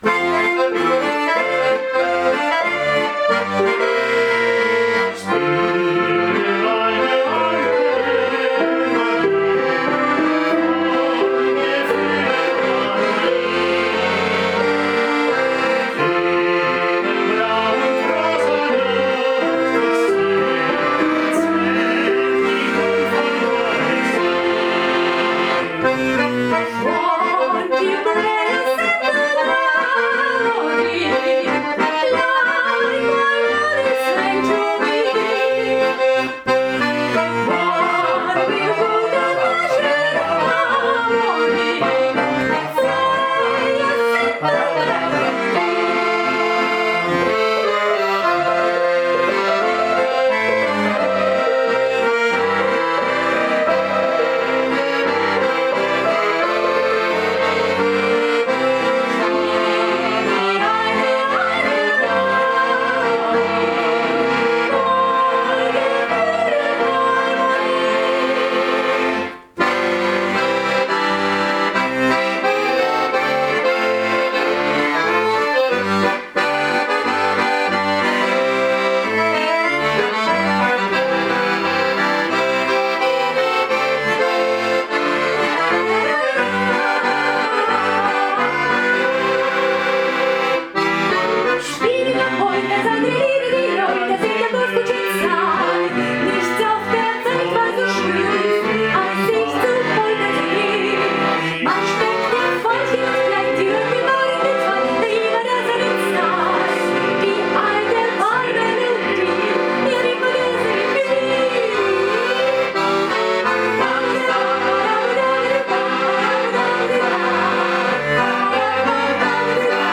• Akkordeon